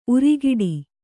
♪ urigiḍi